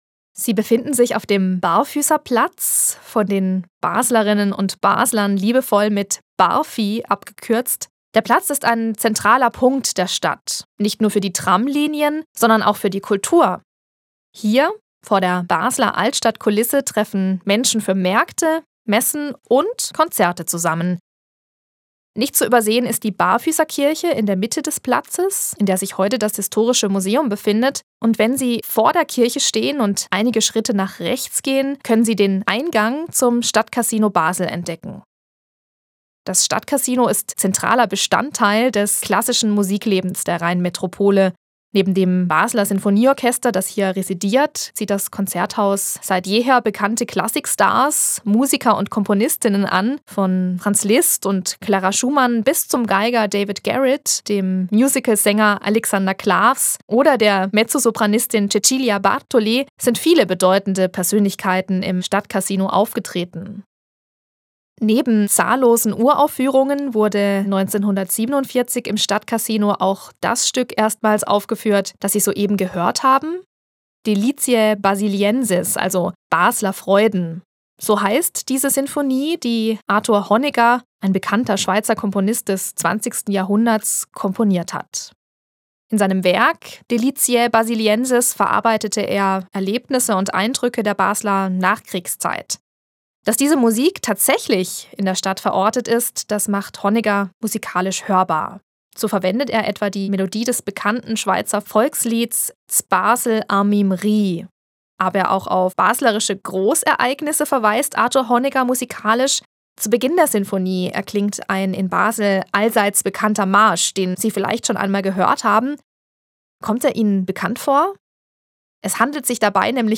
QUELLEN der Musikbeispiele: